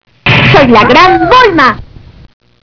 Por motivos de espacion estos sonidos estan hechos en formato wav de 8 bits, por eso es que no tienen mucha calidad, si quieres oir las versiones mas claras, solo Escribeme Y yo te mando los MP3 sin ningun problema.